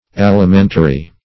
Alimentary \Al`i*men"ta*ry\, a. [L. alimentarius, fr. alimentum: